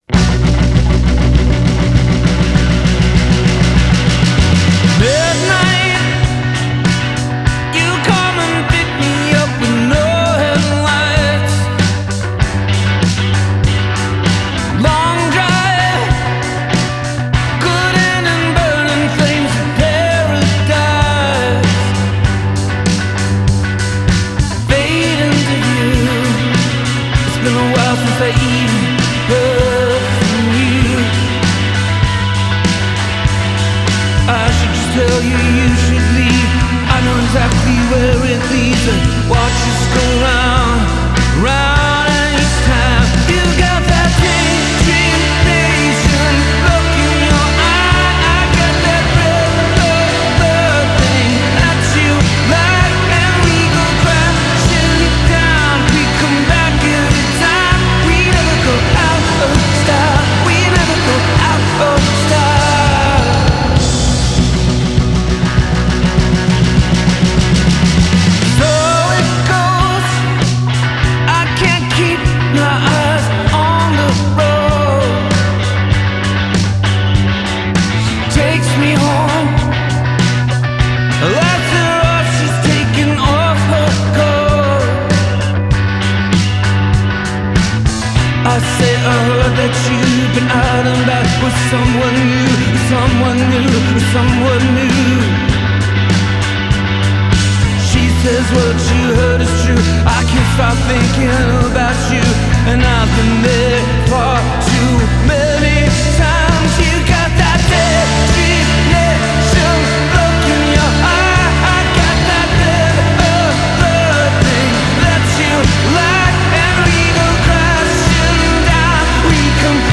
Genre: Rock, Folk, Pop, Covers